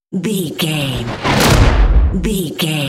Dramatic whoosh to hit trailer
Sound Effects
dark
intense
tension
woosh to hit